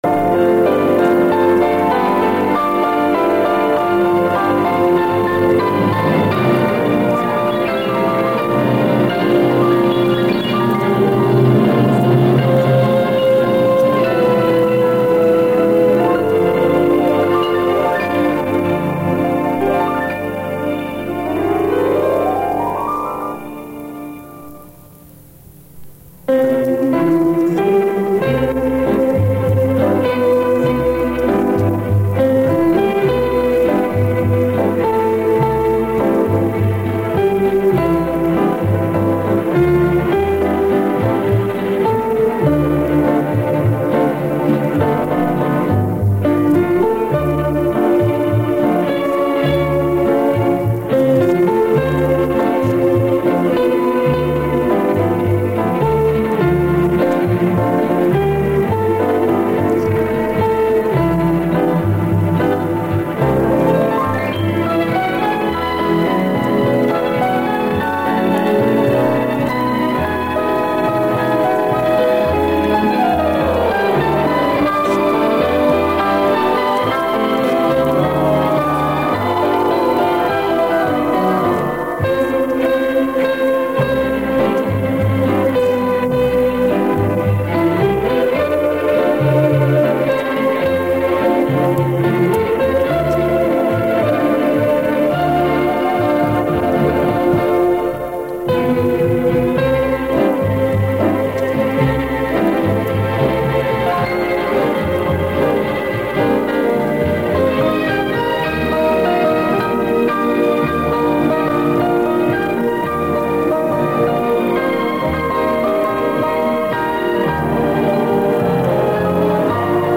Все они с катушек магнитных лент, записанные в 70е года.
3. Эстрадный оркестр исполняет произведение под названием